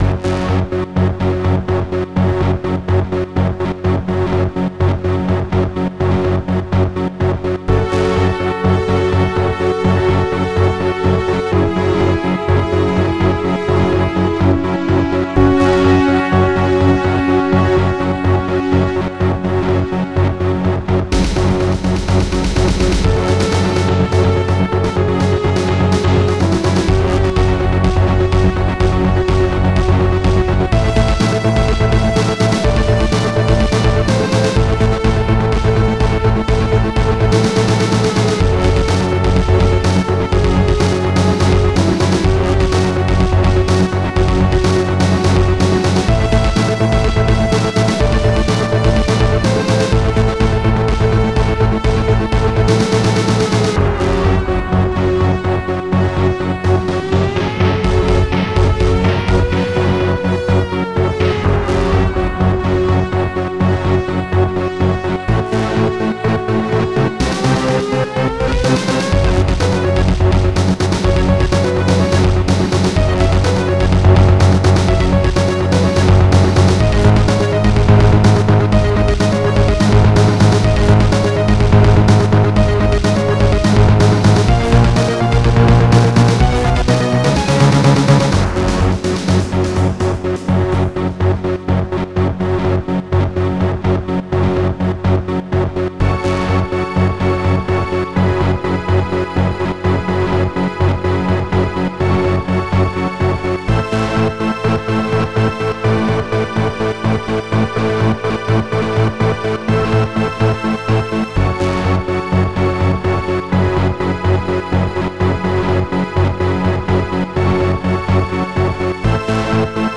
Protracker Module
nsi-basedrum1 dep.Bass1 nsi-snare1 technostring DW.DarkSynth